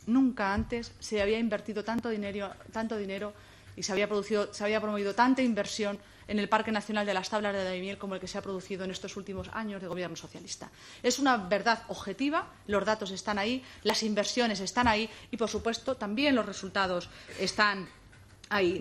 Cortes de audio de la rueda de prensa
Cristina-Maestre-2.mp3